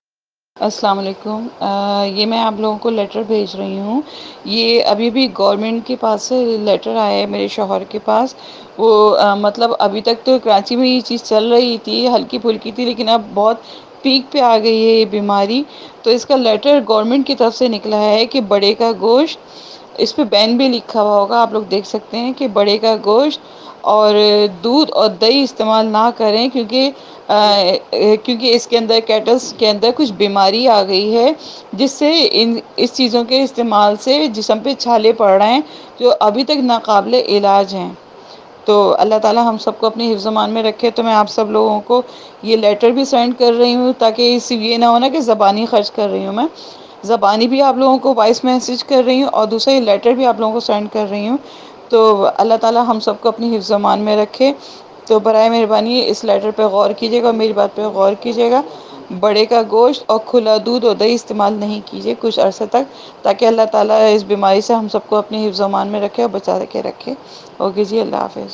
Claim: A WhatsApp voice message warns people not to consume beef, loose milk, and yoghurt due to the Lumpy Skin Disease Virus (LSDV) that has infected cattle in Karachi and can transmit to humans, causing blisters.
On 8 March 2022, Soch Fact Check received a voice message on WhatsApp explaining that a disease has spread among cattle in Karachi and claiming that it can transmit to humans on consumption of cow meat, loose milk or yoghurt. According to the message, once transmitted, the disease causes blisters to appear on the human body.